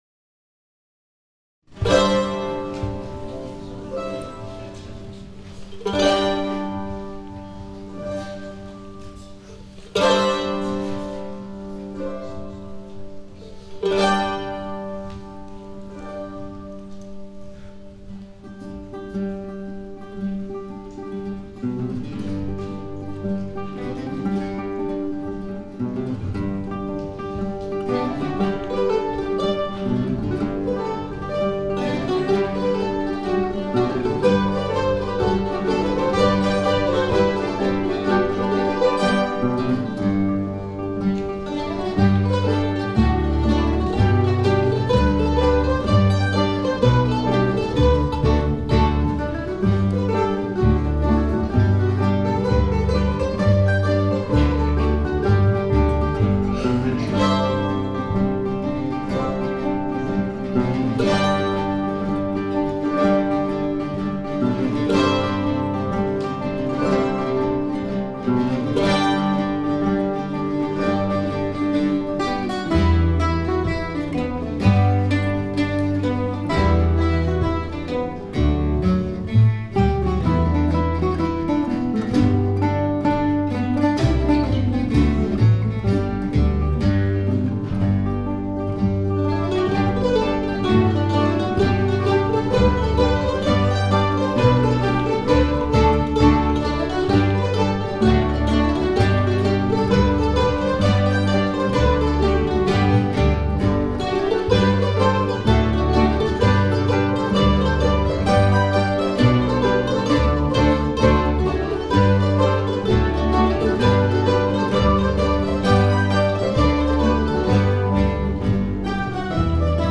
In January 2013 the orchestra played a concert as part of the Music at Lily Pads series in Peace Dale, RI that featured a program of all original music for mandolin and guitars, including some recent award-winning pieces.